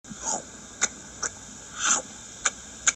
Download Food sound effect for free.